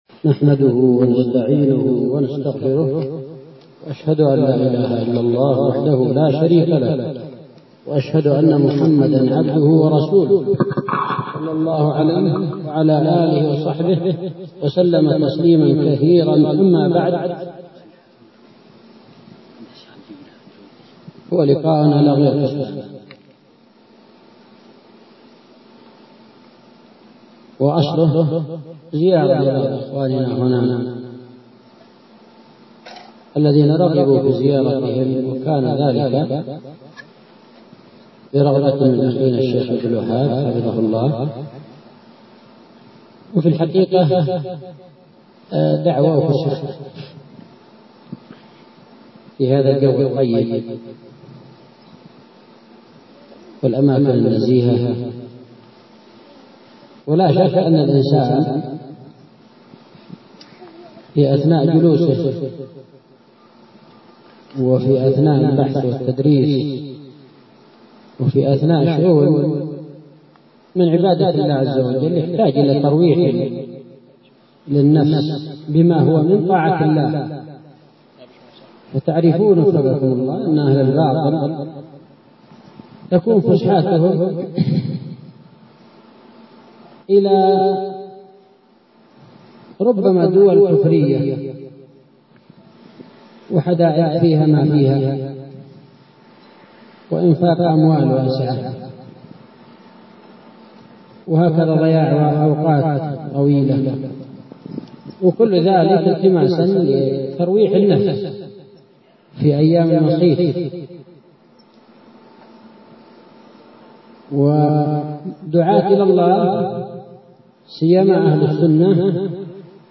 محاضرة في الطائف بعنوان: رمضان شهر التوبة و الغفران سجلت هذه المحاضرة ليلة السبت 28 شعبان 1437هـ